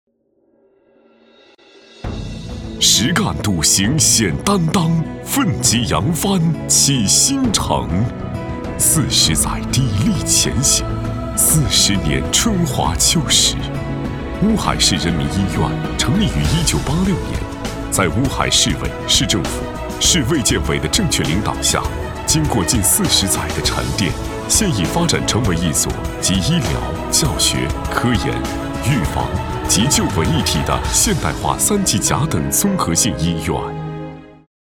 A男44号
【专题】大气专题 实干笃行显担当.mp3